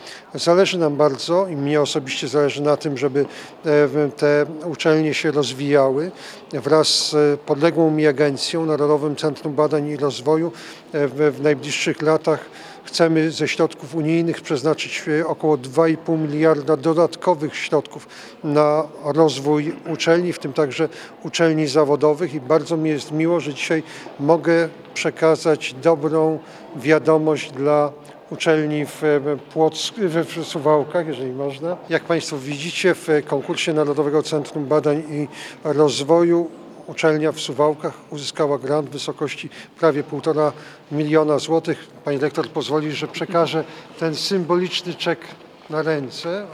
Podczas oficjalnego wystąpienia Jarosław Gowin mówił, że nowe przepisy położą szczególny nacisk na równomierny rozwój uczelni i wzrost poziomu nauczania.